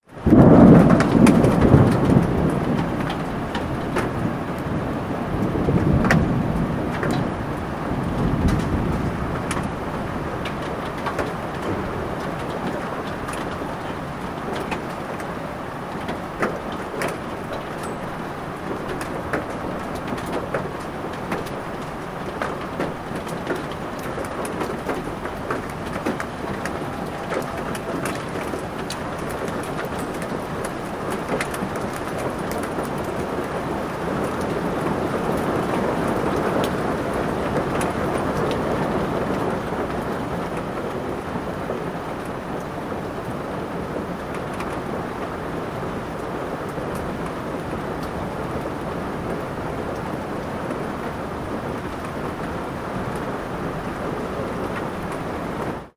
Звуки града
Грохот грозы с градом